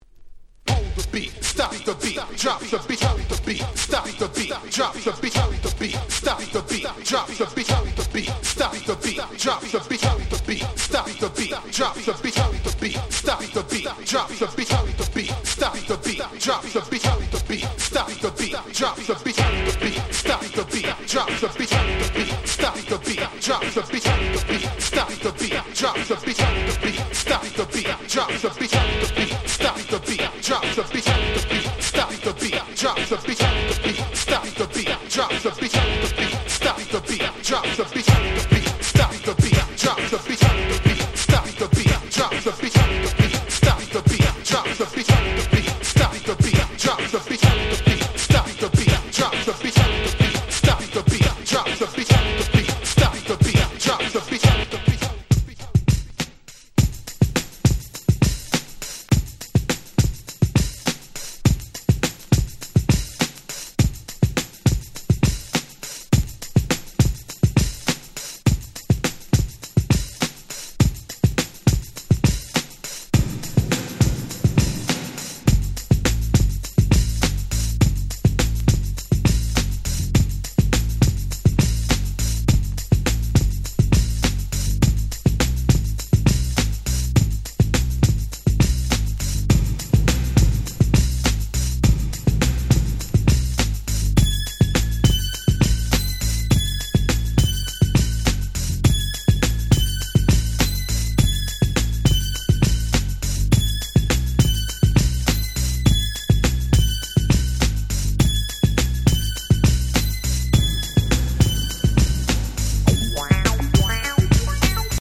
90' UK R&B Classic !!
グラウンドビート Ground Beat